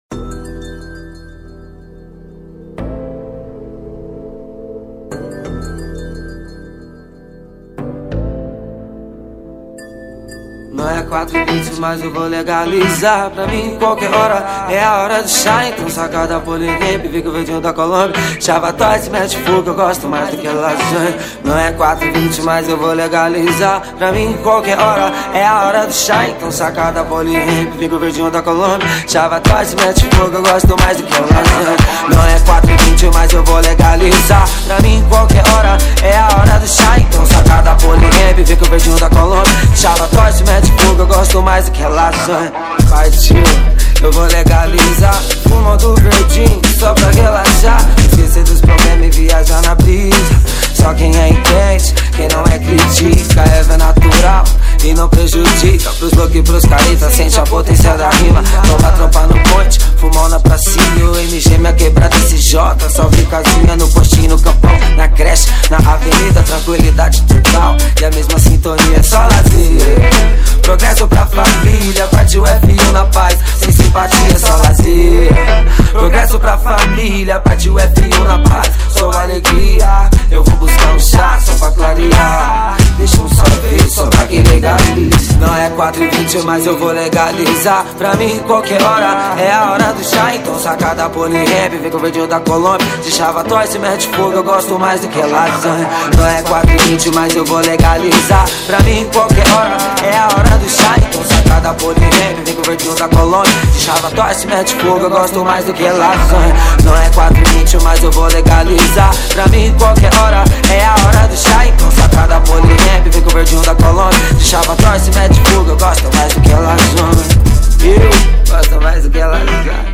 2024-03-01 04:24:14 Gênero: Rap Views